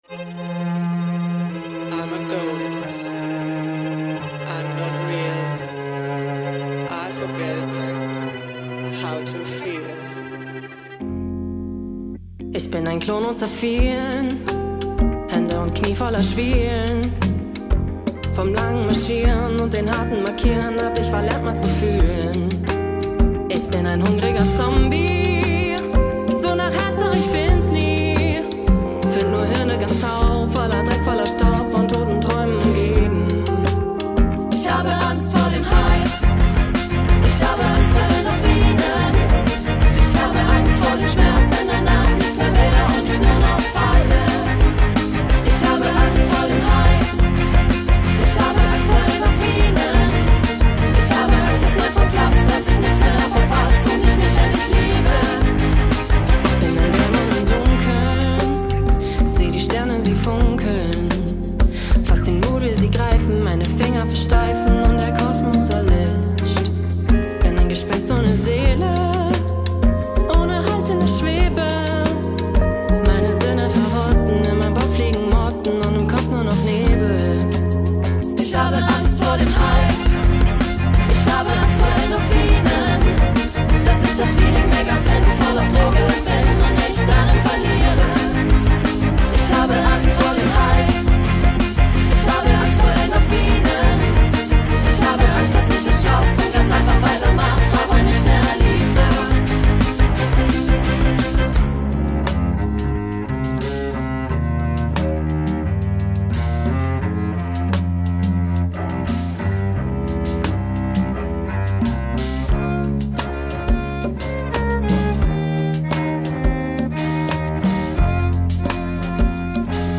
Die Gruppe formiert sich jedes Semester neu und komponiert im Kollektiv einen Song. Dieser wird an einem Blocktag professionell im Studio eingespielt und beim Semesterabschlusskonzert performt.